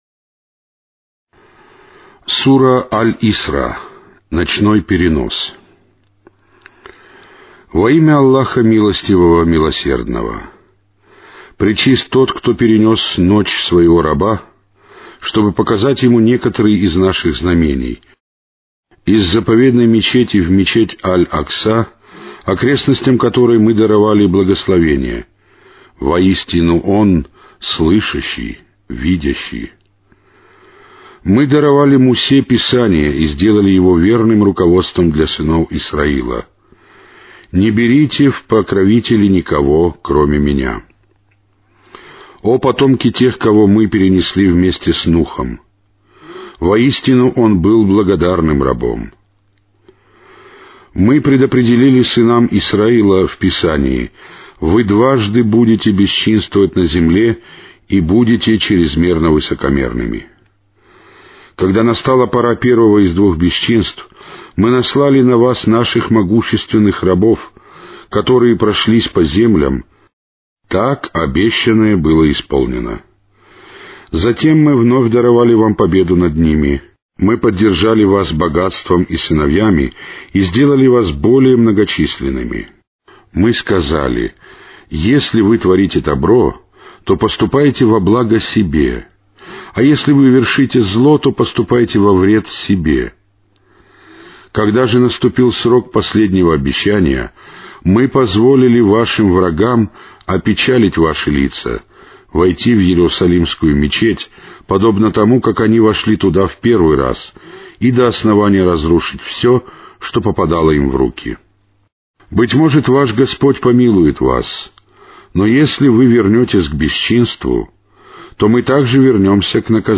Каналы 1 (Mono).